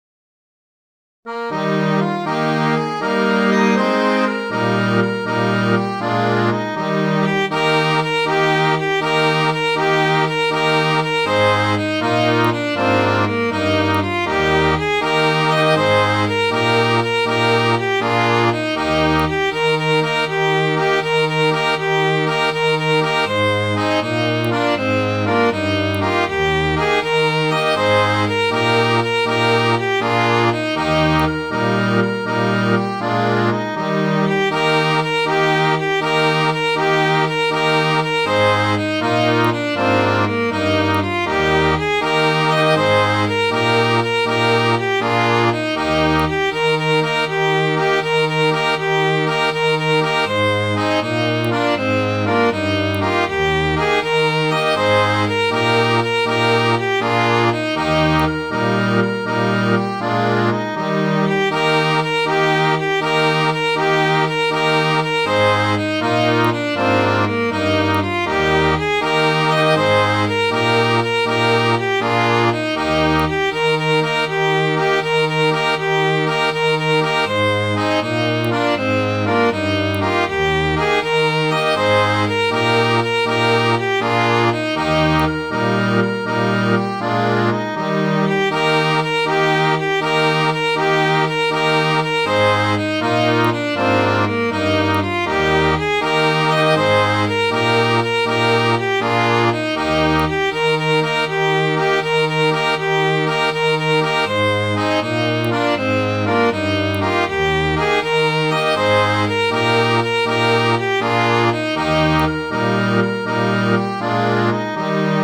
Midi File, Lyrics and Information to The Sailor's Loves
sailorlove.mid.ogg